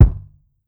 Bouncy Kick.wav